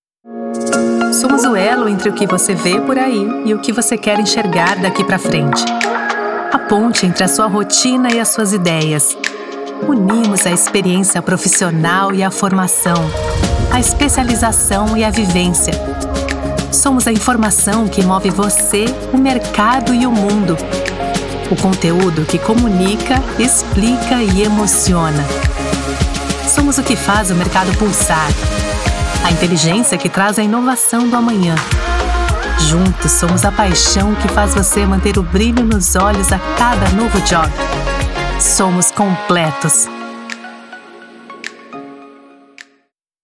Feminino
Voz Jovem 00:43
Voz feminina, natural, adulta, sotaque neutro paulista.